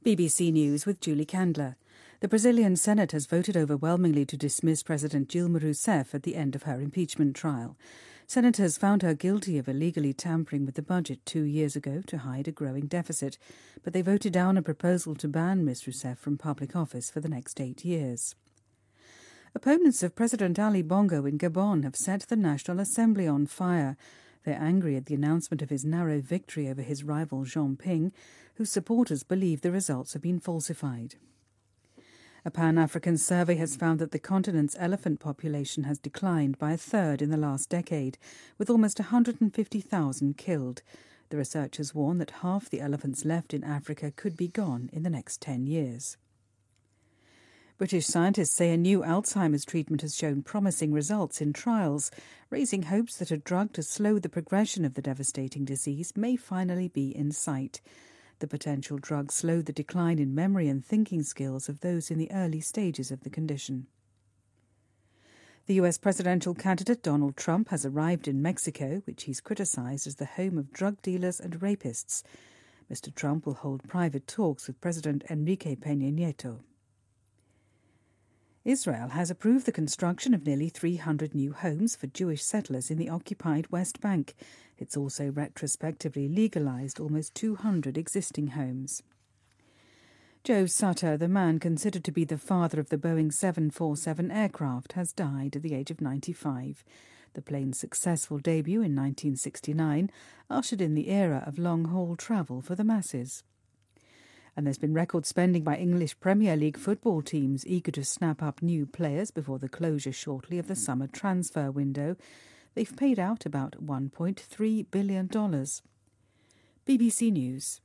日期:2016-09-03来源:BBC新闻听力 编辑:给力英语BBC频道